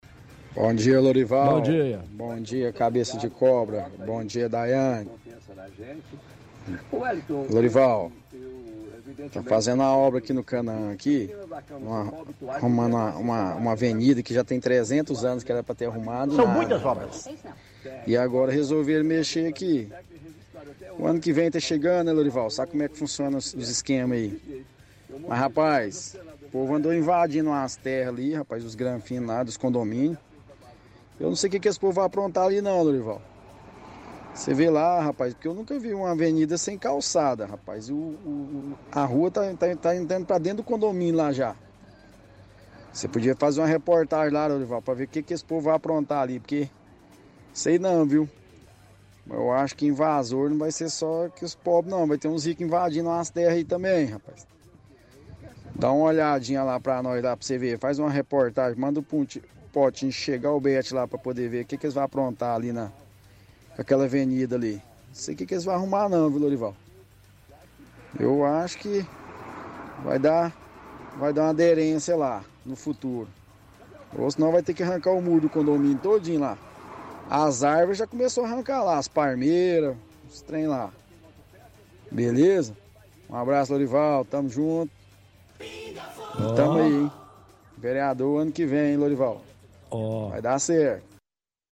– Ouvinte diz que estão arrumando avenida no Canaã, que já deveria ter sido arrumada a muito tempo, mas só está sendo executada agora por estar chegando as eleições. Reclama da falta de calçada na rua.